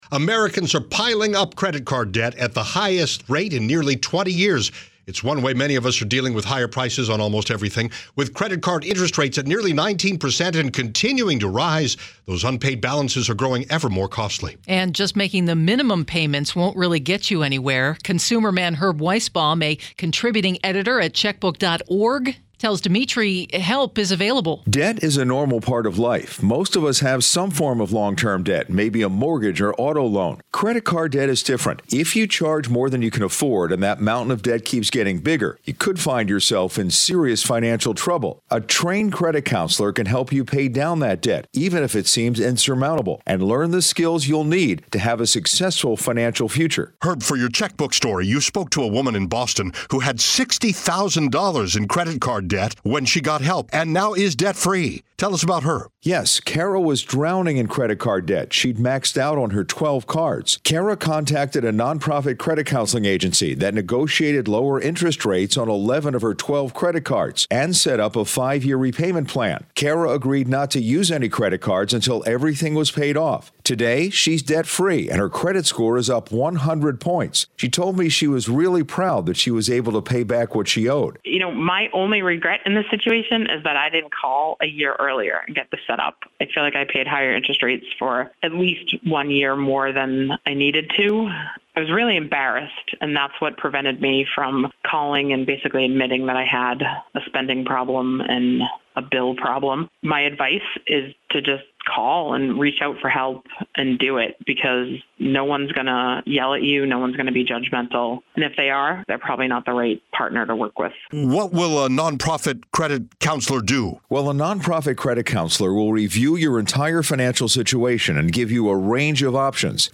WTOP’s Interviews and Links